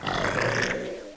c_croc_hit1.wav